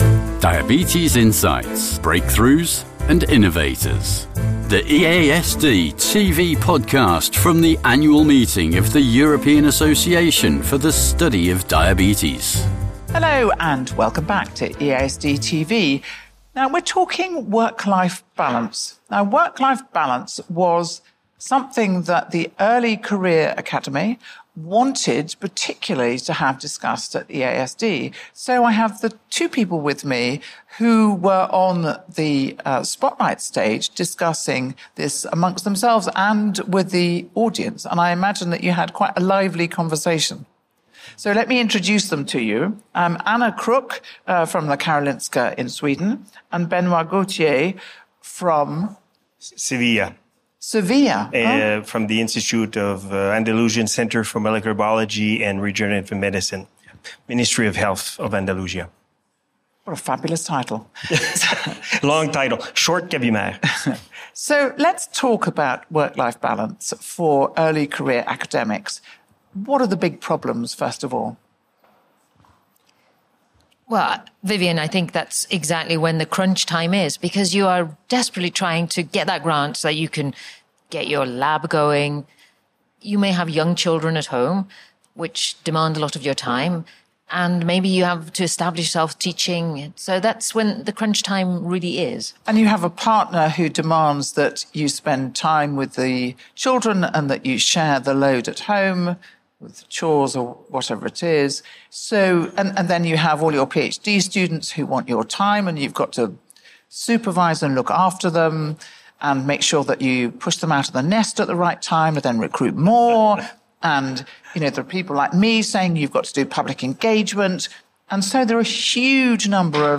We discuss how registries, European-wide collaboration, and real-world screening initiatives are shaping the future of early detection, patient monitoring, and clinical trials. Join us for this insightful conversation on how screening could transform type 1 diabetes care.